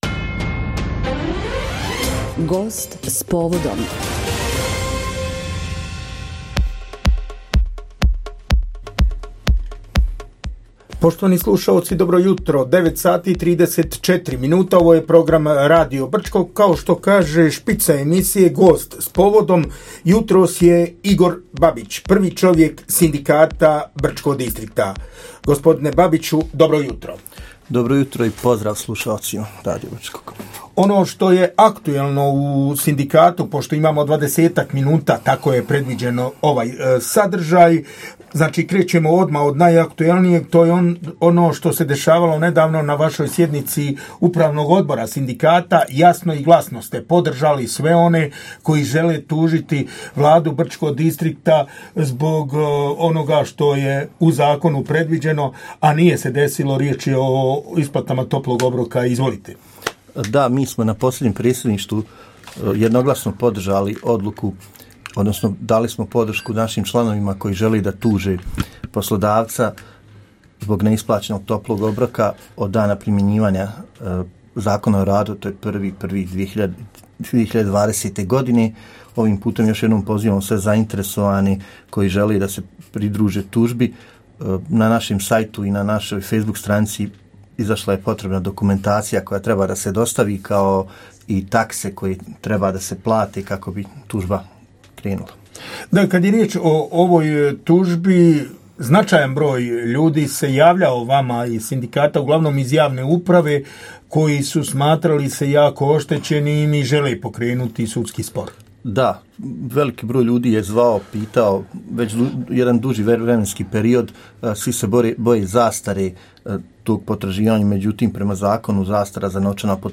Emisija “Gost s povodom” – Razgovor